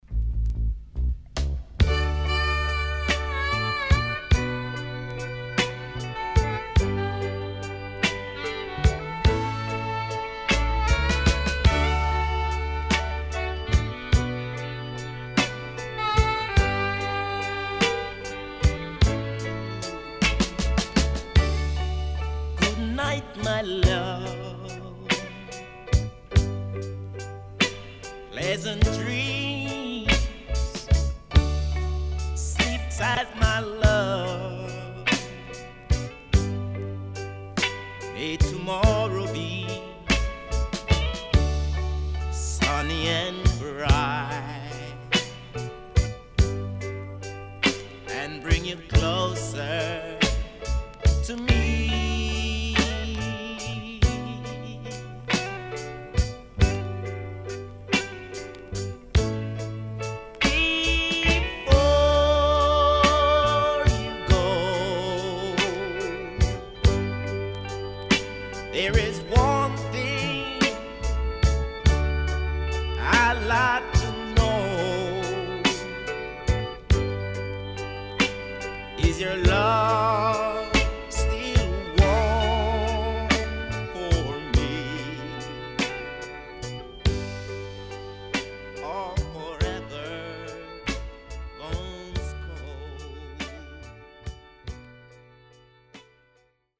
SIDE B:少しノイズ入りますが良好です。